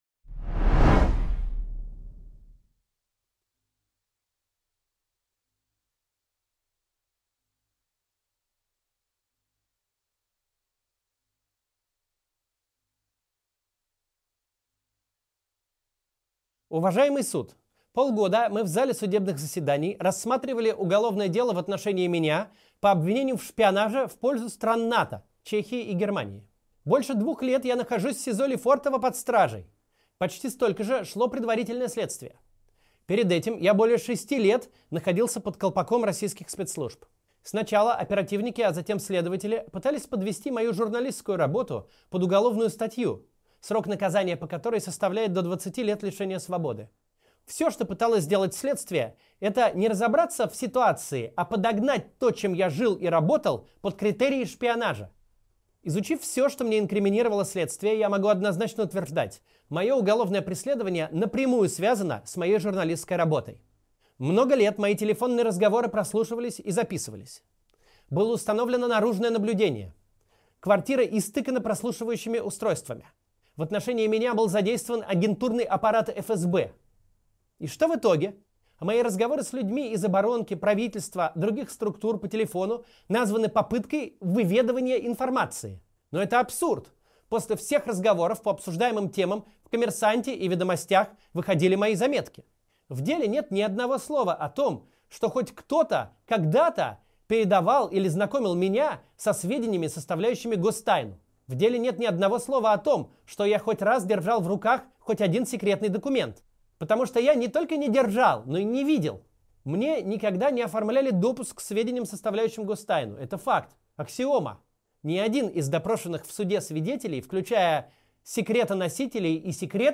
Читает Максим Кац